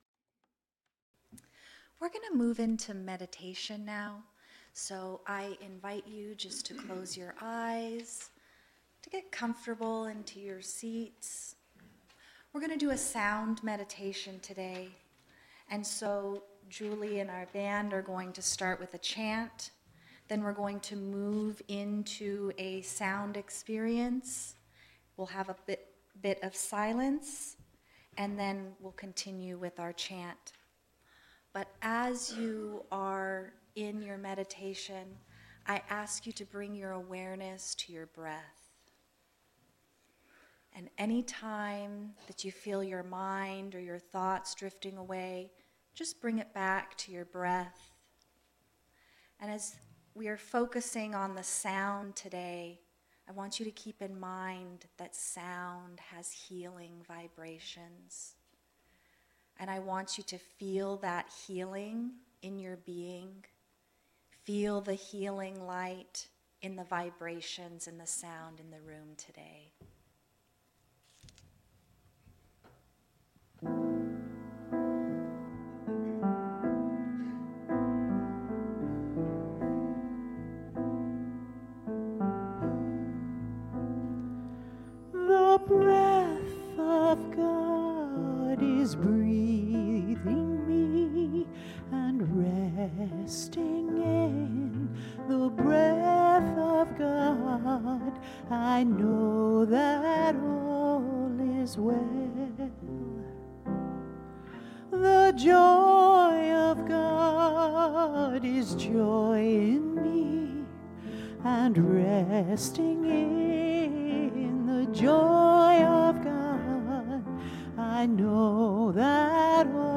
The audio recording (below the video clip) is an abbreviation of the service. It includes the Lesson, Meditation, and Featured Song.